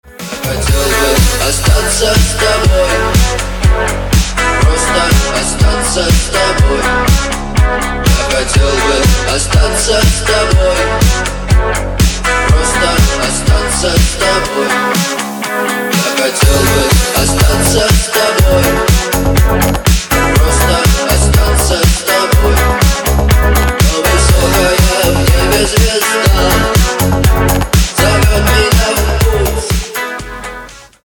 • Качество: 256, Stereo
мужской вокал
deep house
dance
Electronic
EDM
club